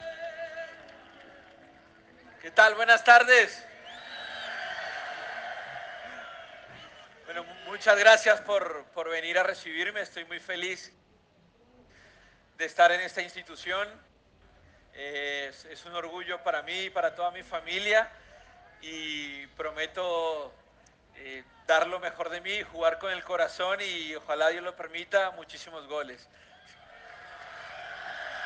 (Falcao García, nuevo delantero del Rayo Vallecano)
Falcao García fue presentado este jueves como nuevo jugador del Rayo Vallecano, en un evento celebrado en el Estadio de Vallecas de Madrid, donde hace las veces de local el cuadro rayista.